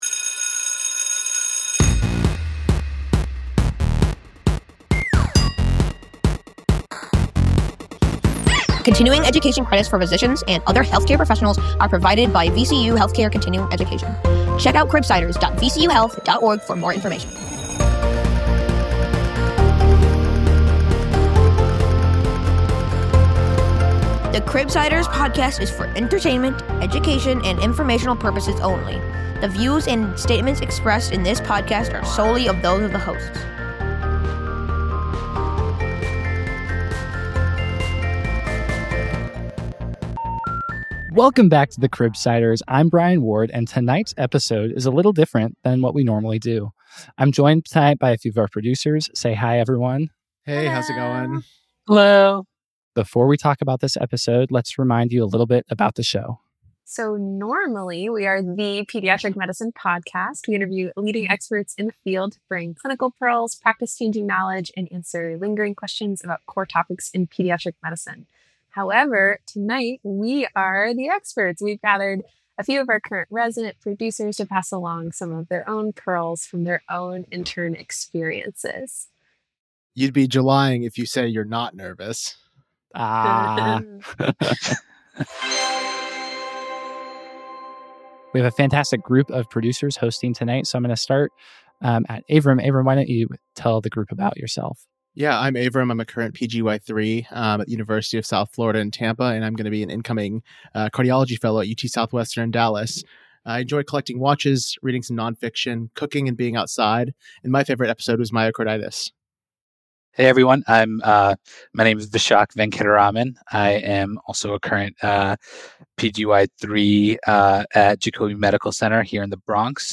In this special Fables from the Cribside episode, our team of resident producers talk tips and tricks for new pediatrics interns. We cover big feelings, finding your pre-rounding groove, avoiding night-shift tummy, and remind you to call your mom!